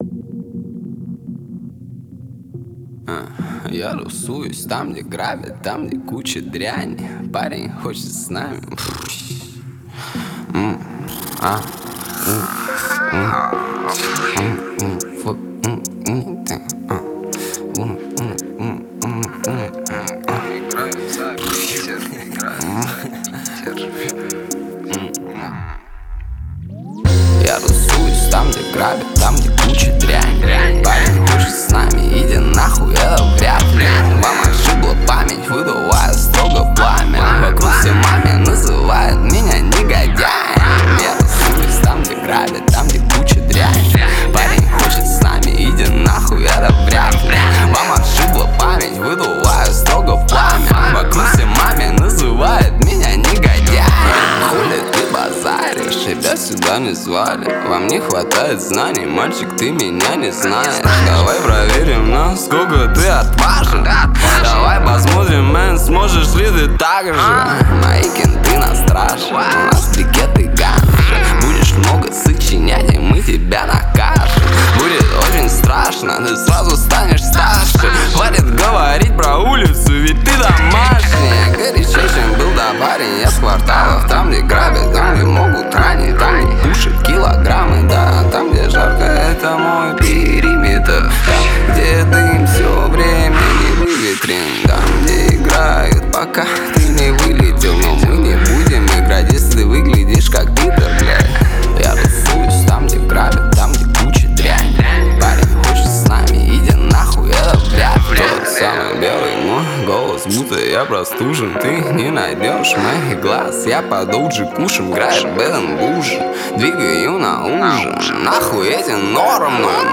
Жанр: Популярная музыка